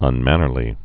(ŭn-mănər-lē)